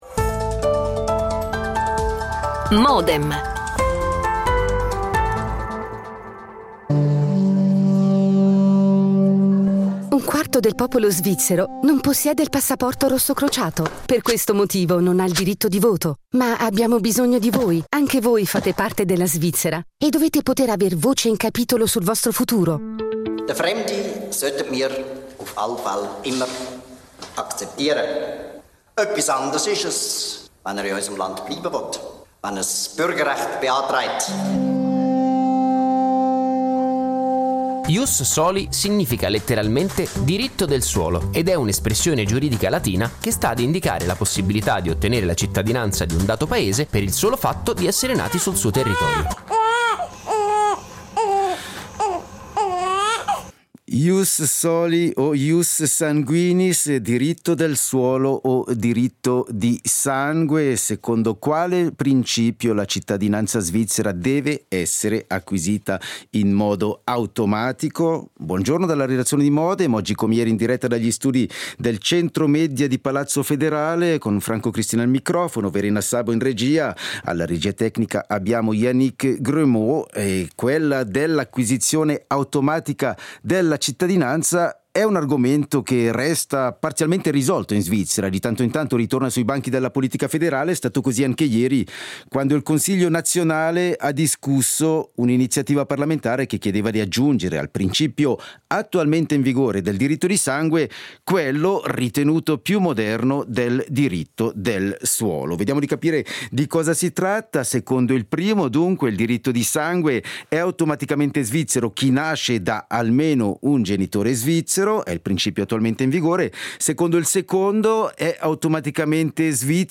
Ne discutiamo con tre consiglieri nazionali membri della commissione delle istituzioni politiche.
L'attualità approfondita, in diretta, tutte le mattine, da lunedì a venerdì